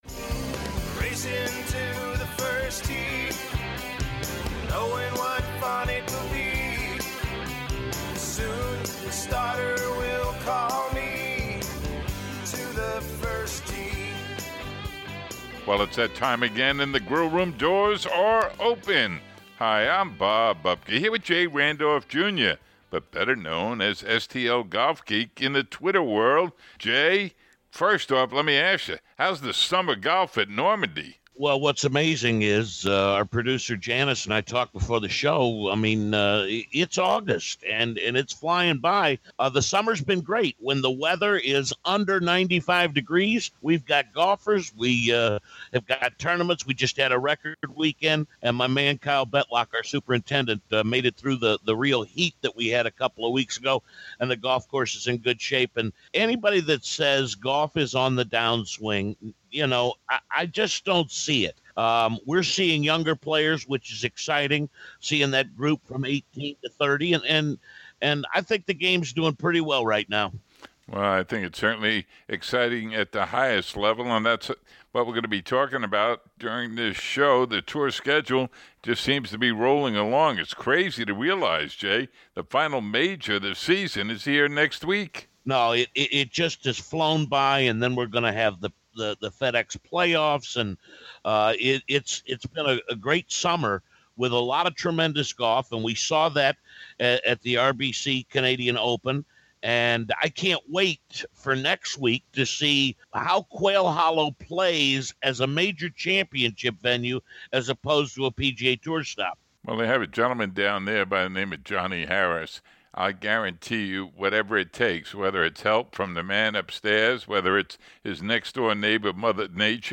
Next up: a preview of the Bridgestone Invitational and 2017 PGA Championship. Feature Interview